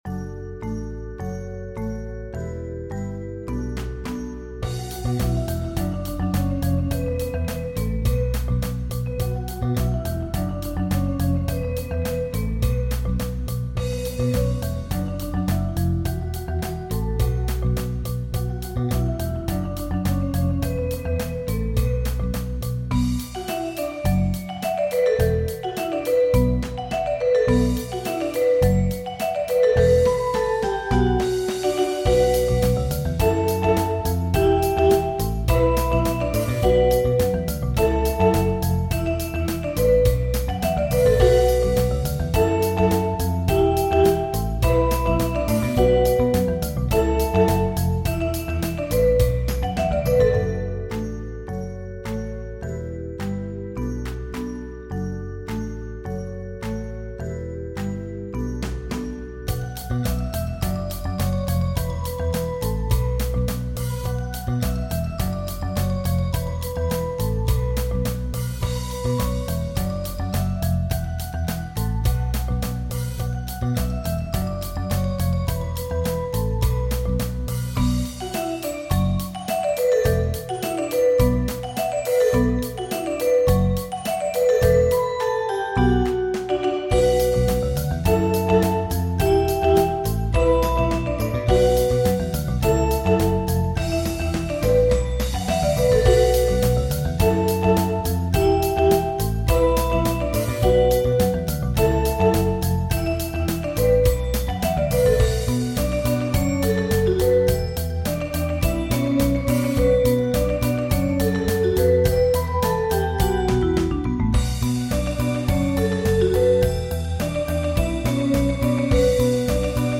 Mallet-Steelband Muziek